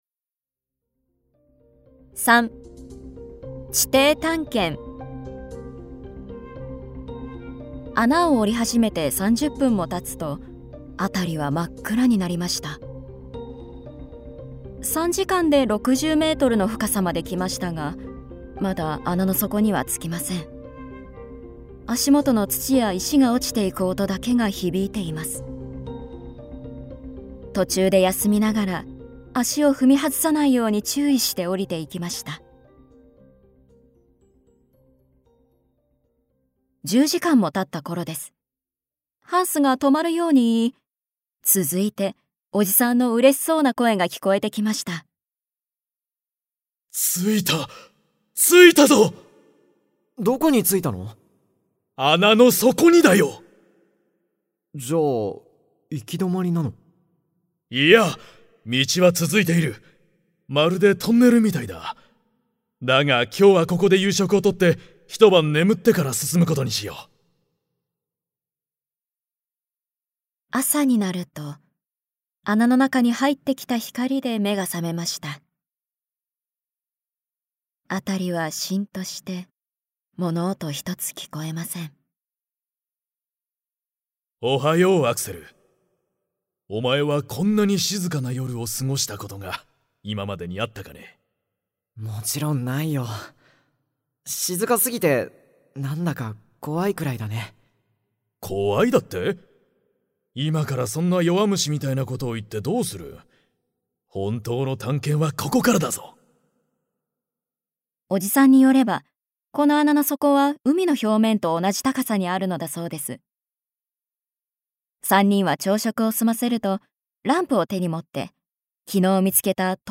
[オーディオブック] 地底旅行（こどものための聴く名作41）